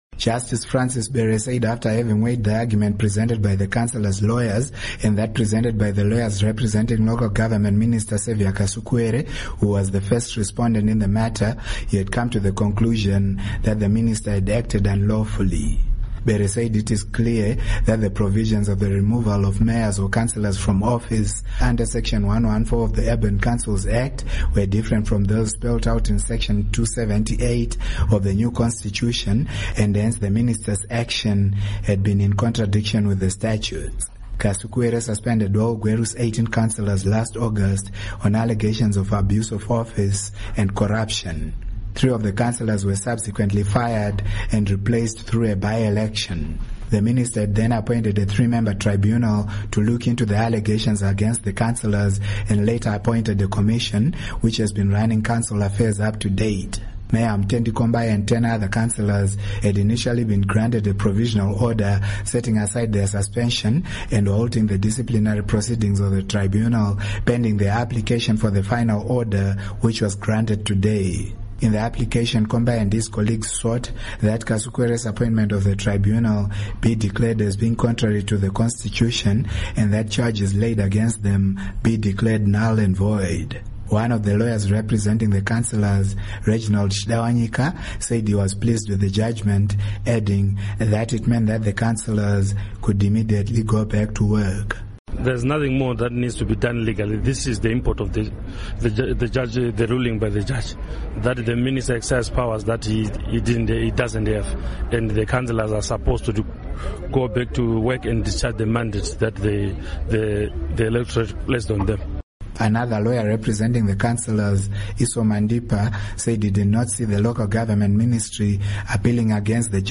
Report on High Court Ruling on Gweru Councillors